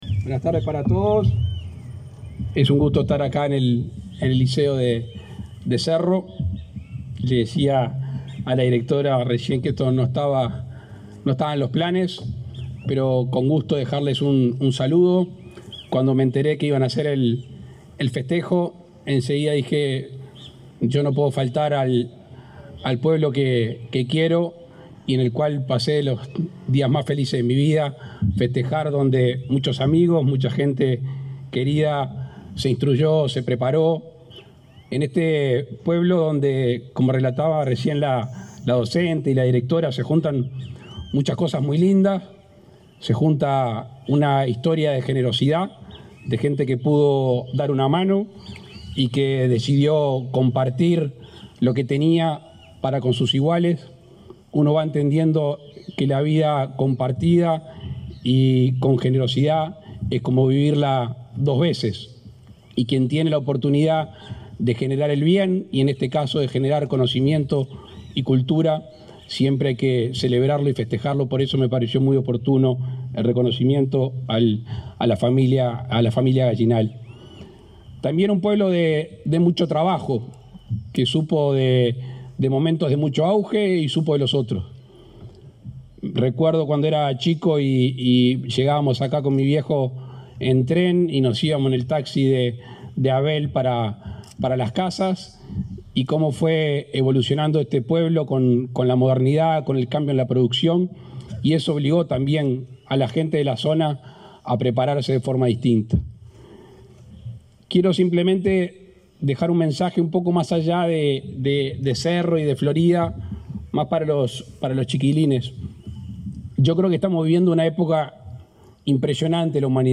El presidente Luis Lacalle Pou participó este sábado 20 en el festejo por el cincuentenario del liceo de Cerro Colorado, en Florida.